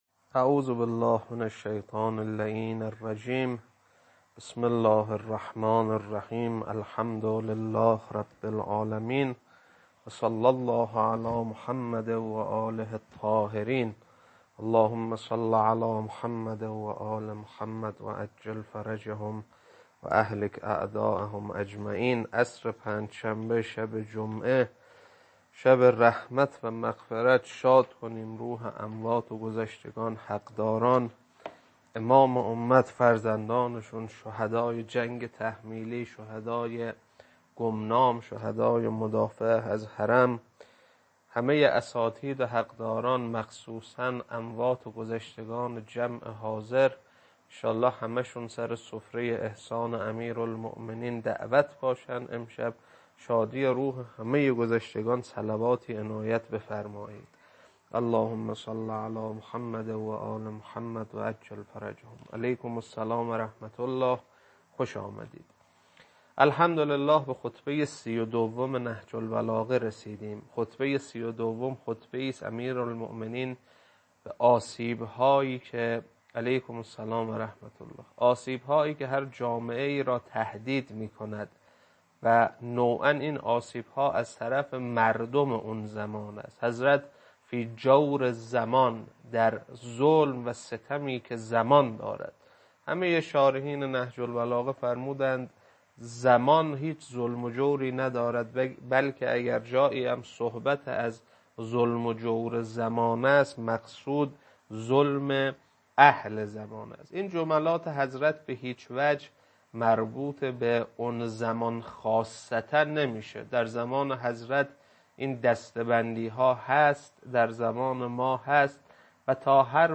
خطبه 32.mp3